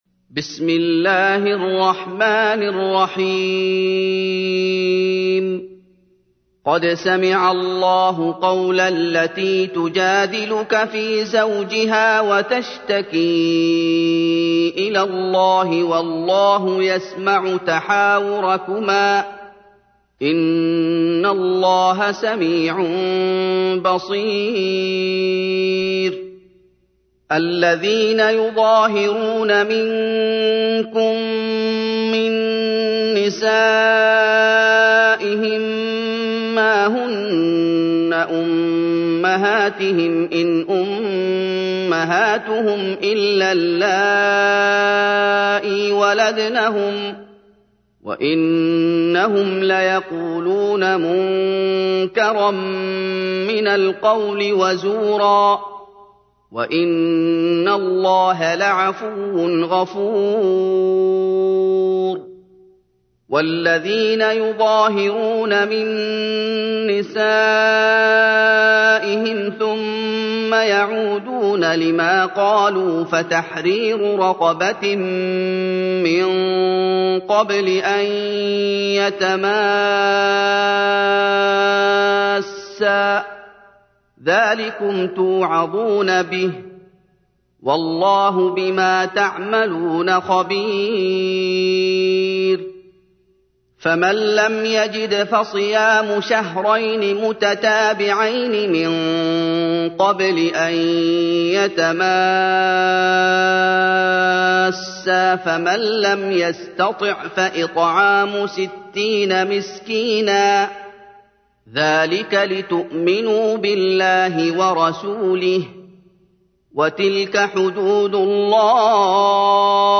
تحميل : 58. سورة المجادلة / القارئ محمد أيوب / القرآن الكريم / موقع يا حسين